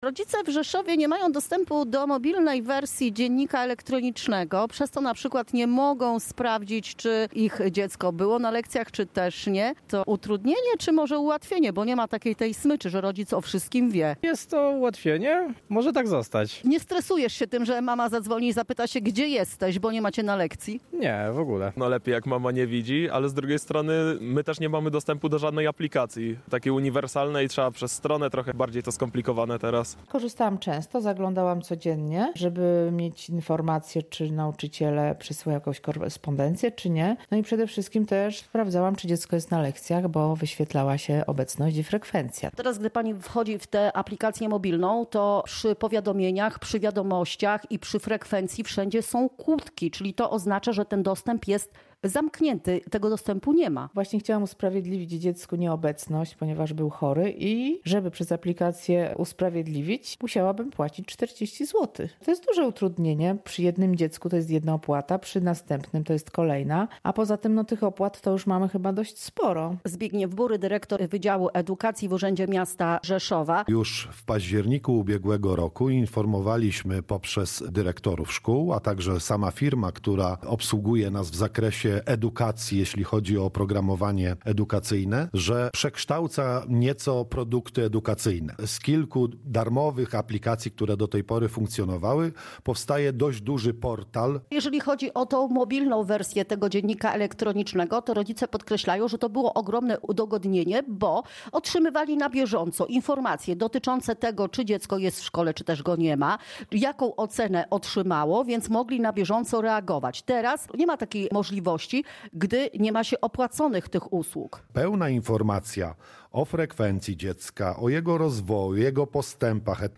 Koniec darmowej aplikacji mobilnej e-dziennik w Rzeszowie • Relacje reporterskie • Polskie Radio Rzeszów